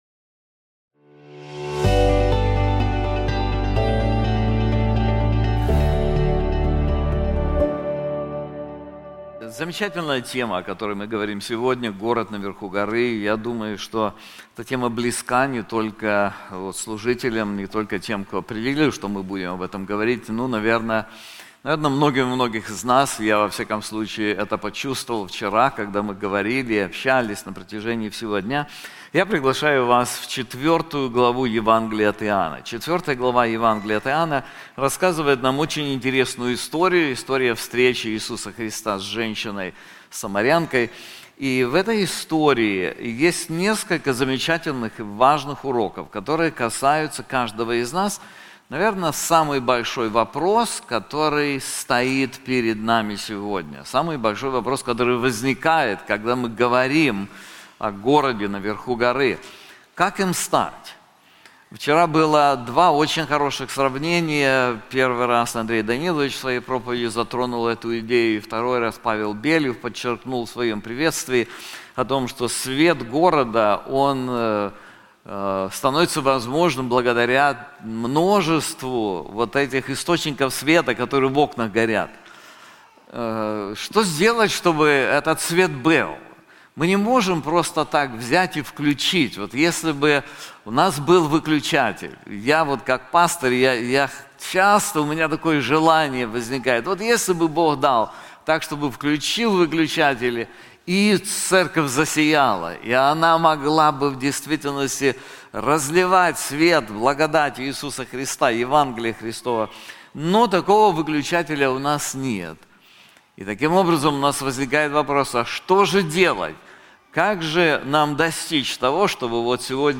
This sermon is also available in English:The Spring of Living Water • John 4:14